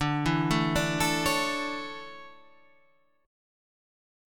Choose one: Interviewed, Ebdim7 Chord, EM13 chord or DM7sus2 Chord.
DM7sus2 Chord